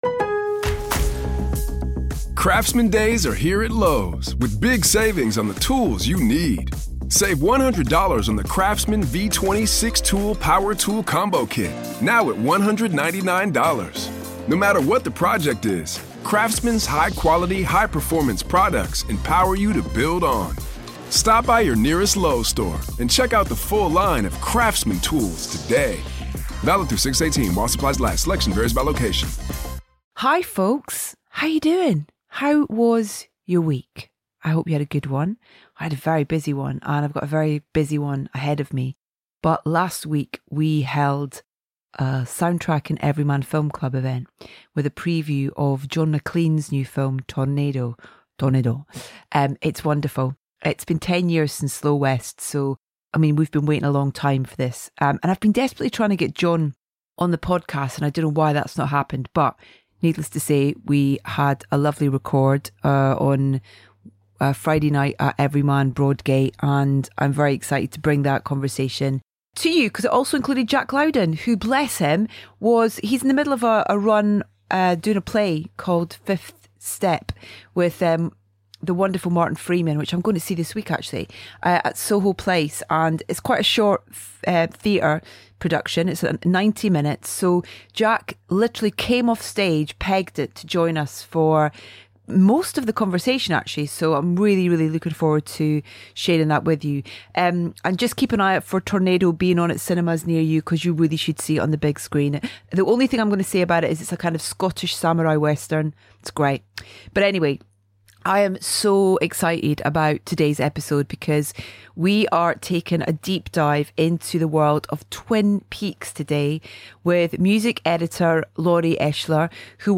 It's an illuminating chat, shedding bright light on David's process and reminding us all what a seminal show it was and remains.